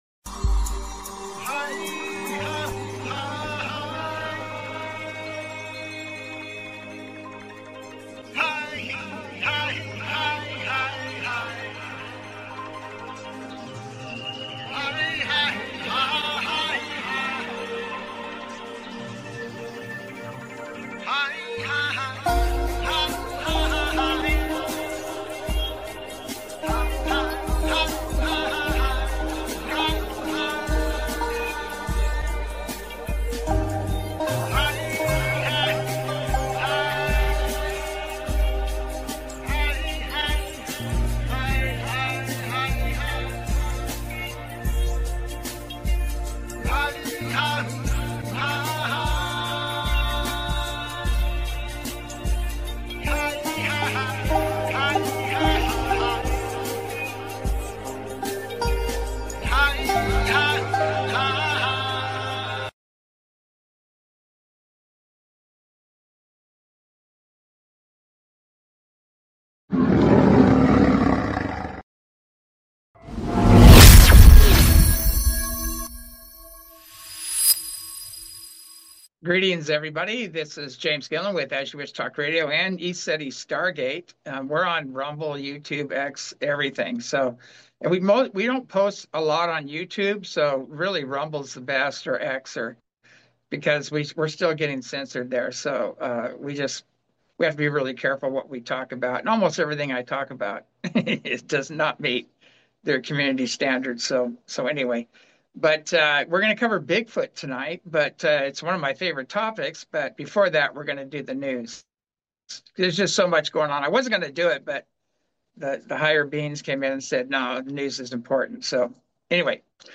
Talk Show Episode, Audio Podcast, As You Wish Talk Radio and Bigfoot Paranorma and Mt. Adams on , show guests , about Bigfoot Paranorma,Mt. Adams, categorized as Earth & Space,News,Paranormal,UFOs,Philosophy,Politics & Government,Science,Spiritual,Theory & Conspiracy
As you Wish Talk Radio, cutting edge authors, healers & scientists broadcasted Live from the ECETI ranch, an internationally known UFO & Paranormal hot spot.